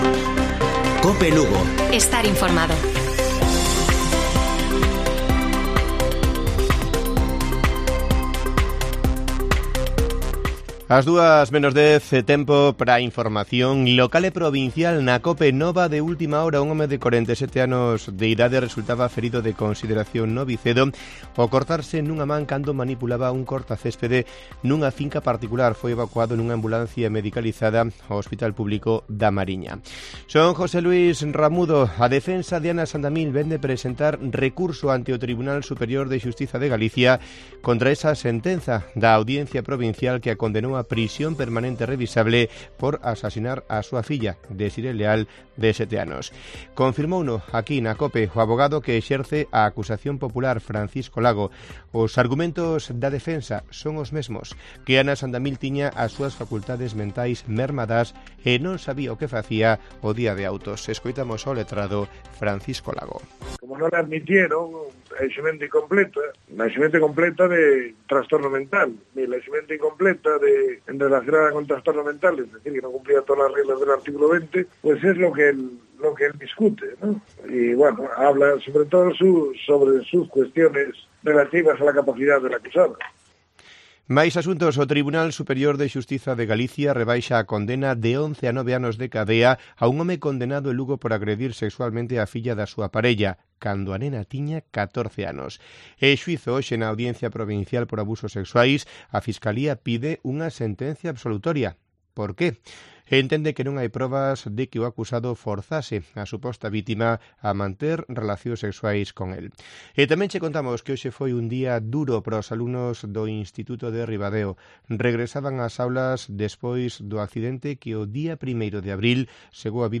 Informativo Mediodía de Cope Lugo. 11 de abril. 13:50 horas